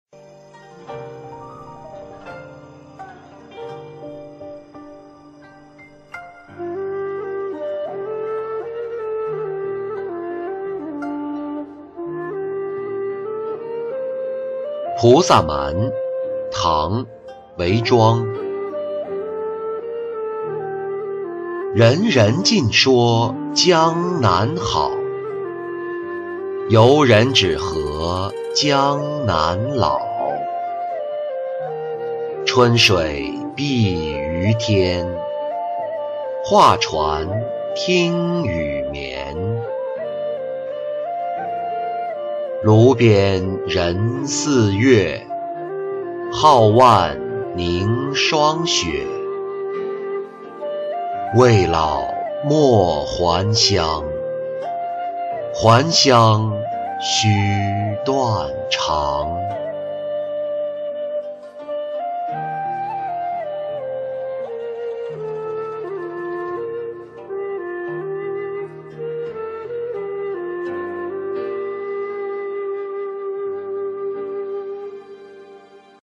菩萨蛮·人人尽说江南好-音频朗读